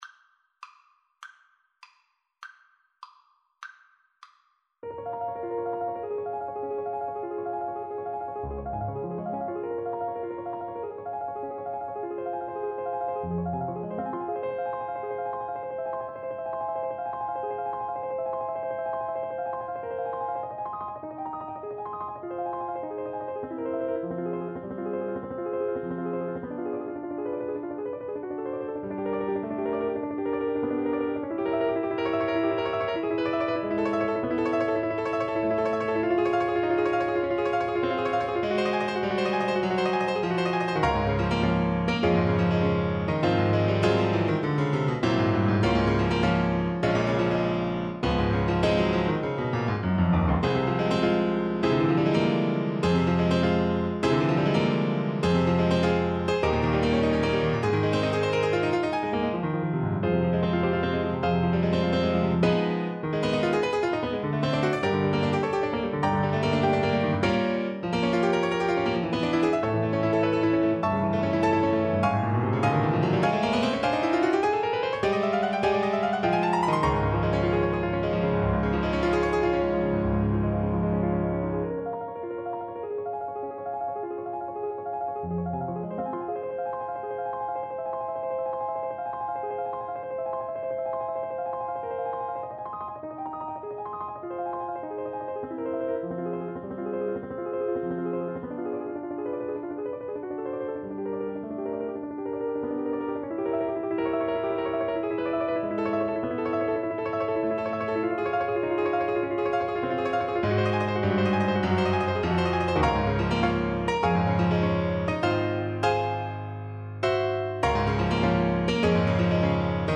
Play (or use space bar on your keyboard) Pause Music Playalong - Piano Accompaniment Playalong Band Accompaniment not yet available transpose reset tempo print settings full screen
Clarinet
Agitato =100-112
Db major (Sounding Pitch) Eb major (Clarinet in Bb) (View more Db major Music for Clarinet )
2/4 (View more 2/4 Music)
Classical (View more Classical Clarinet Music)